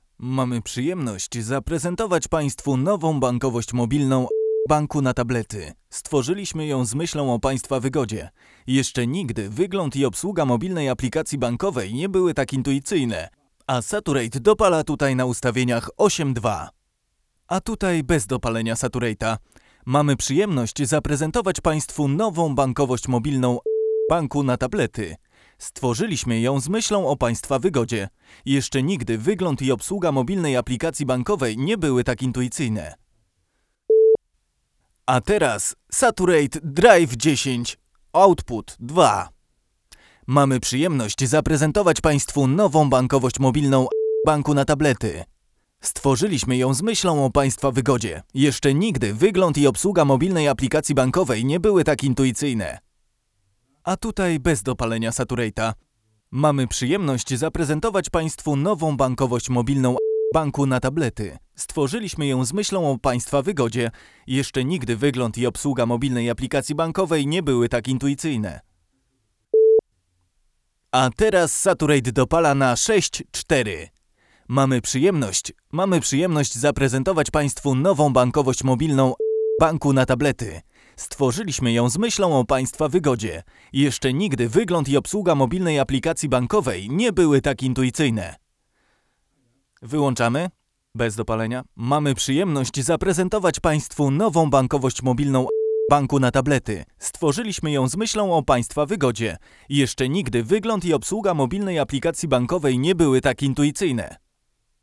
Od lekkiego podkolorowania po brudny przester.
Tor nagraniowy: Neumann U89 > UA 6176 > SA2RATE > ECHO AUDIOFIRE PRE8.
Jak słychać, różnica jest dość wyraźna choć używaliśmy go dość delikatnie. W nagraniu lektora mamy teraz więcej „mięcha” i „piachu".